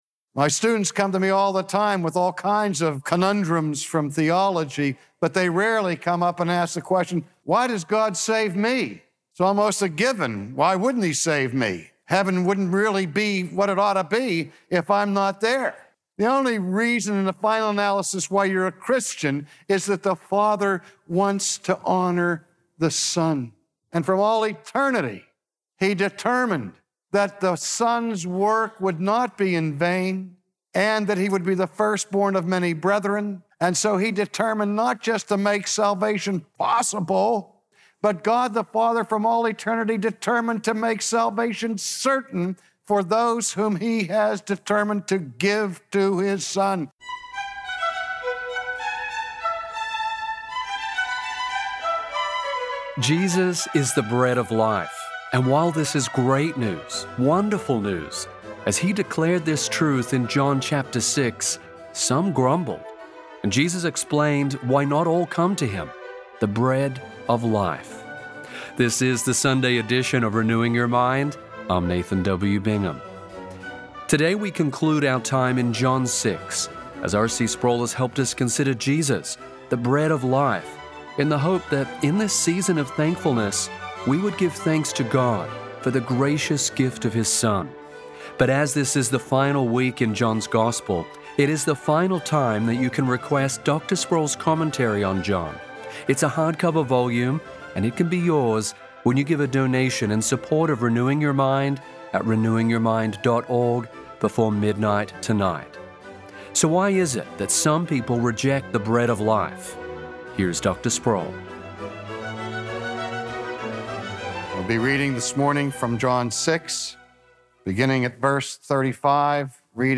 Only Jesus can meet our deepest spiritual hunger. Yet no one will come to Christ for eternal life unless the Father draws them. From his sermon series in the gospel of John